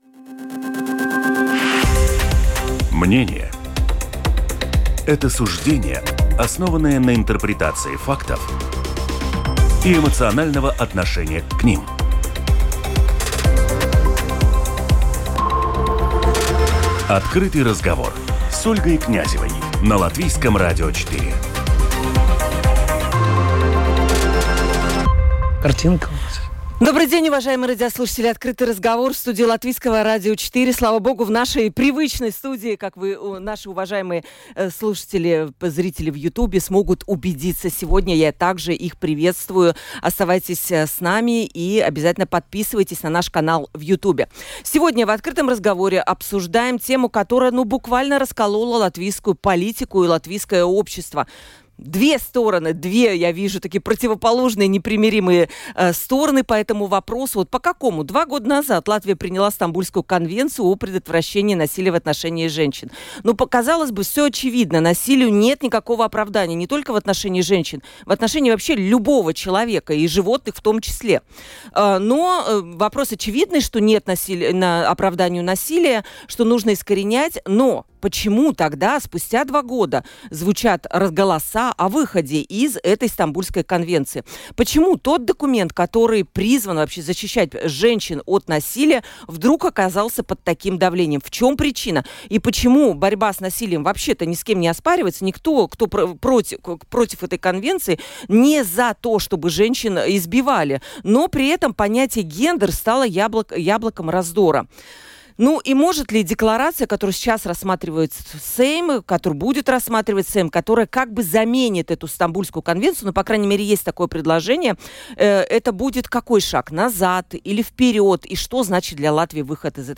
Почему вопрос защиты от насилия вызывает политический раскол? В студии "Открытого разговора"